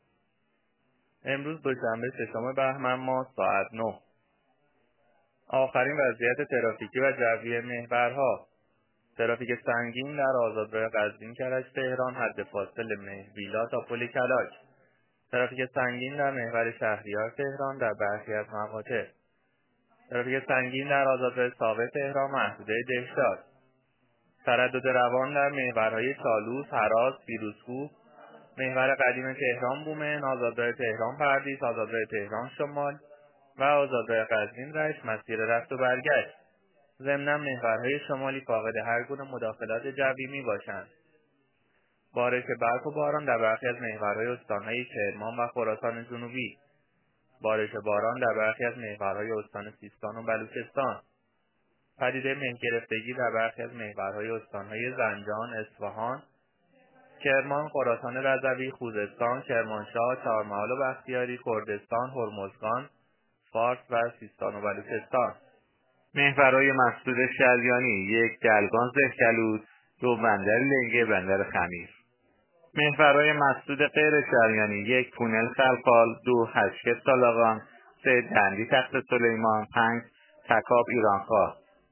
گزارش رادیو اینترنتی از آخرین وضعیت ترافیکی جاده‌ها ساعت ۹ ششم بهمن؛